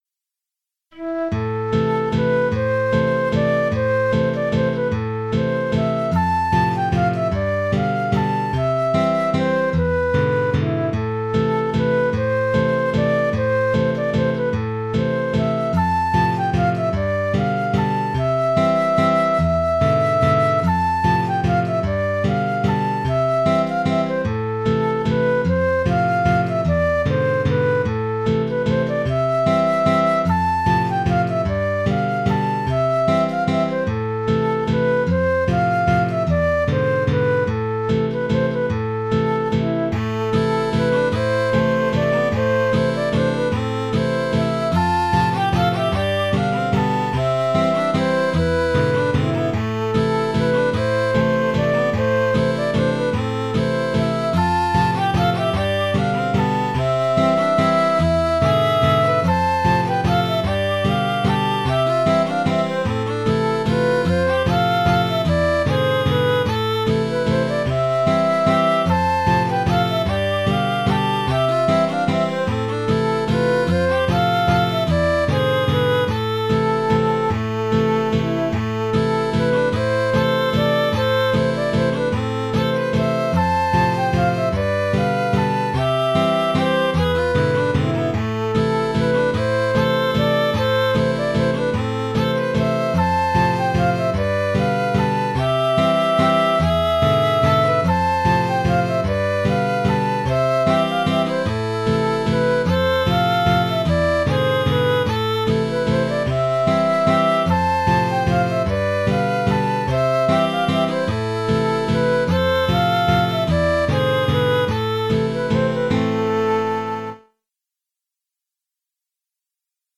Cette valse, issue du folk suédois, est bien connue des accordéonistes diato. Tout en étant simple, sa mélodie permet une richesse harmonique qui lui donne une couleur à la fois mélancolique et joyeuse, avec sa tonalité en la mineur tonal, c’est-à-dire avec l’utilisation de l’accord de mi 7.
Le fichier audio fait entendre d’abord le thème sans contrechant pour bien le mettre en évidence.